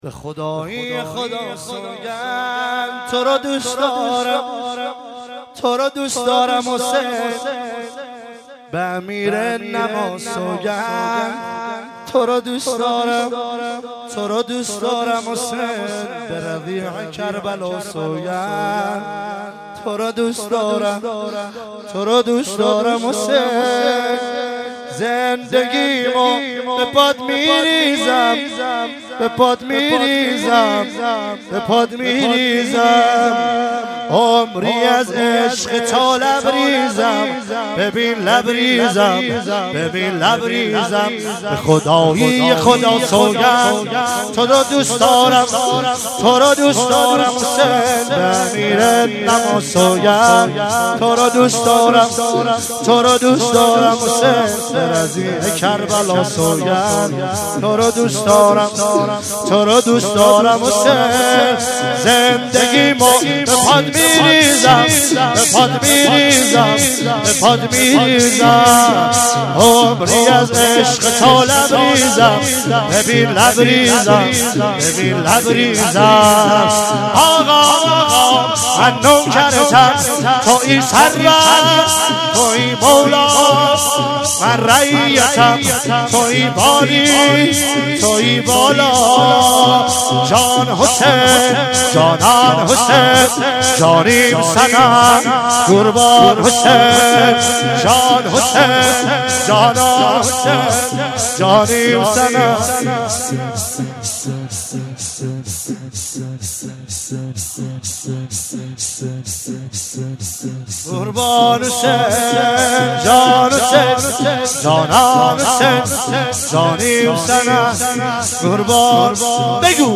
فاطمیه 96 - 17 بهمن - کرمان - شور - به خدایی خدا سوگند